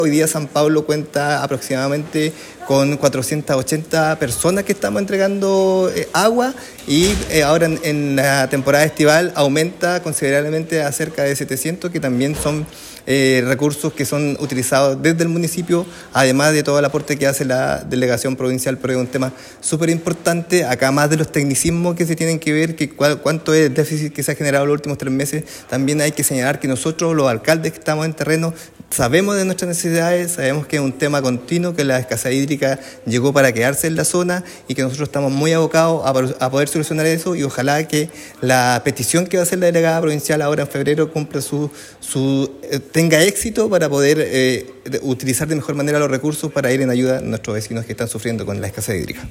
El alcalde también detalló que durante la temporada estival cerca de 700 personas en la comuna de San Pablo dependen de la distribución de agua potable mediante camiones aljibes.